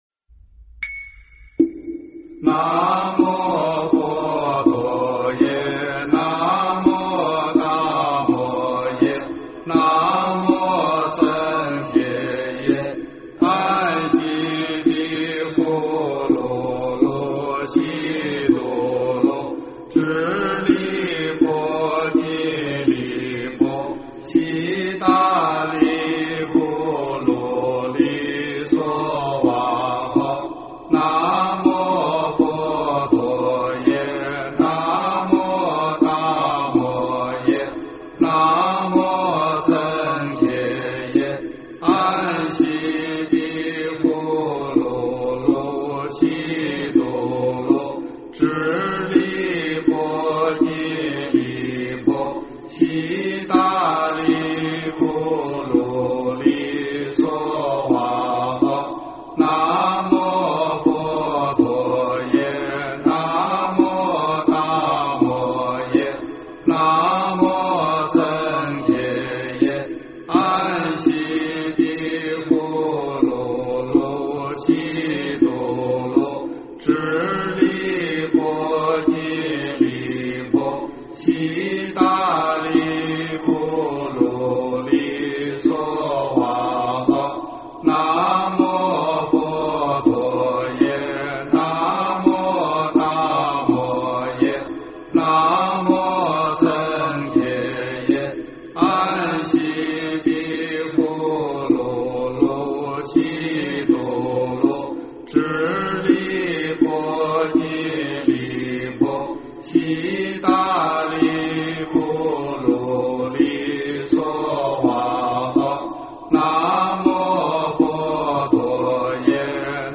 经忏
佛音
佛教音乐